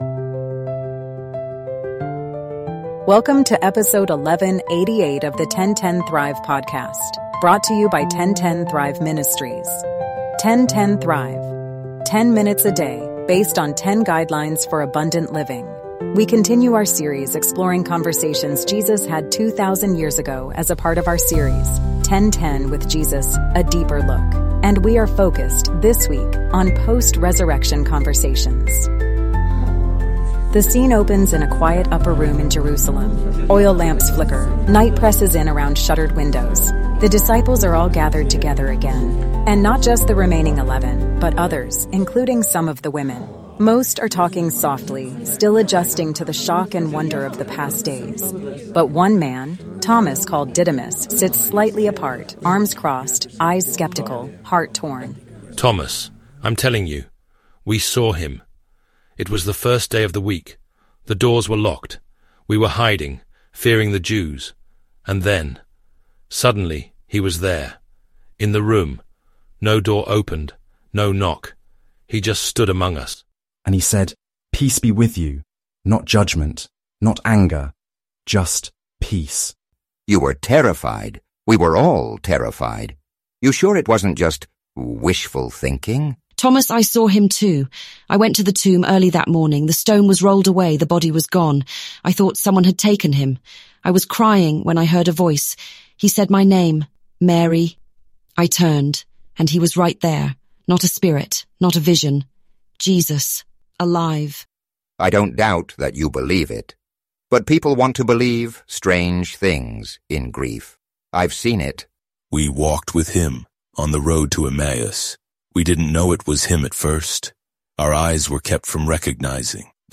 In this episode of the 10:10 Thrive Podcast, listeners are drawn into a dramatic retelling of Thomas's encounter with the risen Jesus. The narrative begins in an upper room in Jerusalem, where the disciples are gathered, still reeling from the resurrection reports.